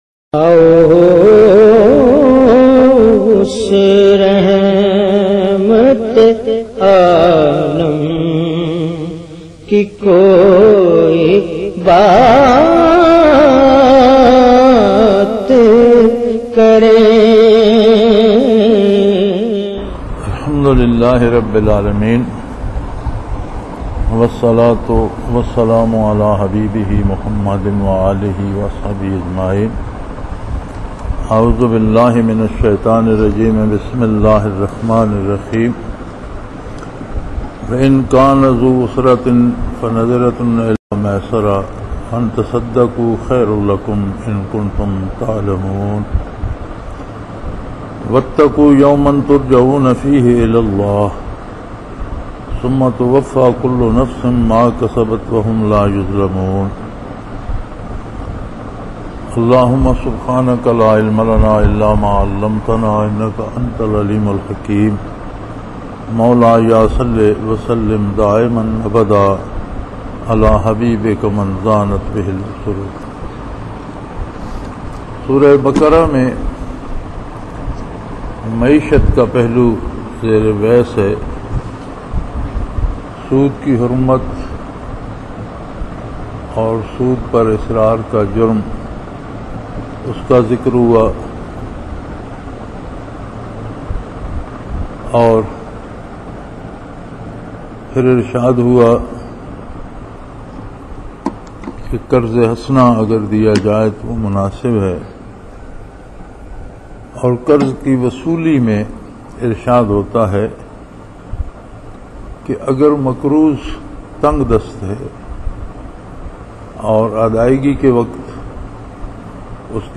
Lectures in Munara, Chakwal, Pakistan